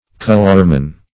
colorman - definition of colorman - synonyms, pronunciation, spelling from Free Dictionary Search Result for " colorman" : The Collaborative International Dictionary of English v.0.48: Colorman \Col"or*man\, n.; pl. Colormen .
colorman.mp3